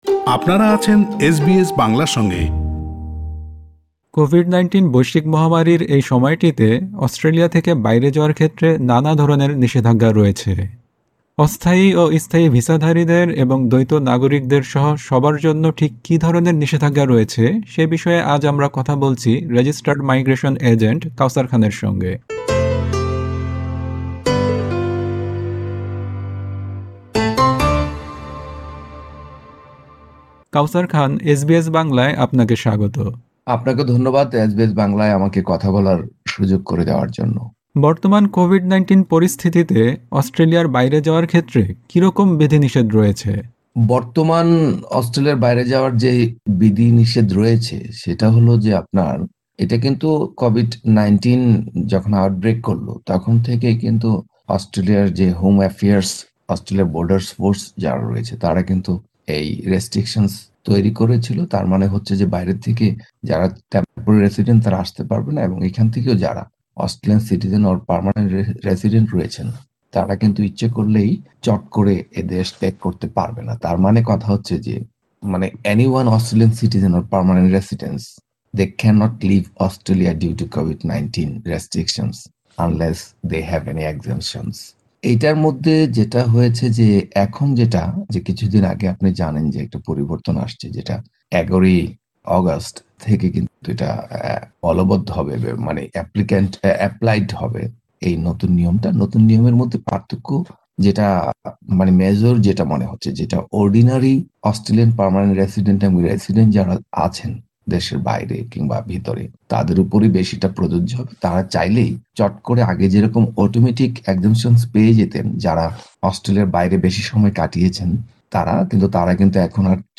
এসবিএস বাংলার সঙ্গে কথা বলেছেন রেজিস্টার্ড মাইগ্রেশন এজেন্ট